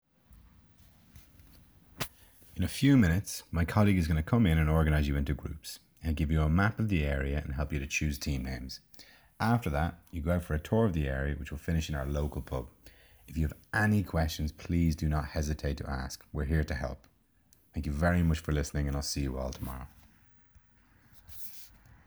7. Practice: For practice I give them another section of my welcome talk.